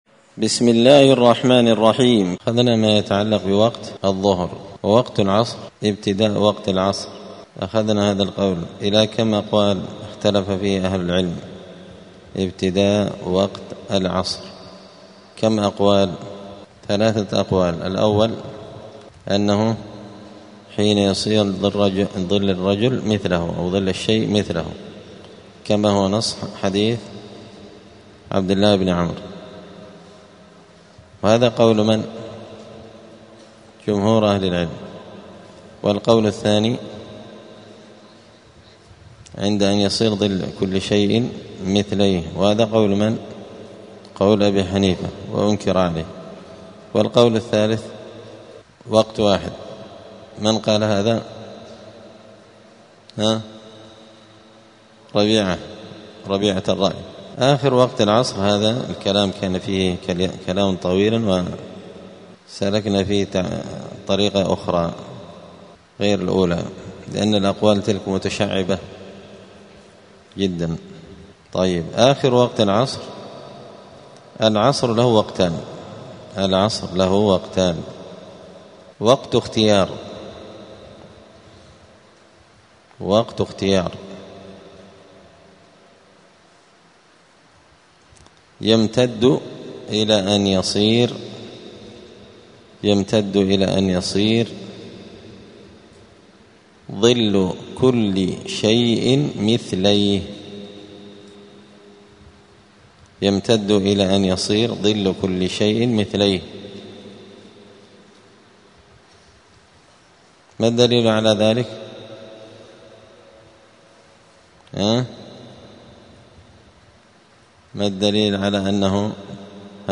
دار الحديث السلفية بمسجد الفرقان قشن المهرة اليمن
*الدرس التاسع والعشرون بعد المائة [129] {العصر له وقتان اختيار واضطرار}*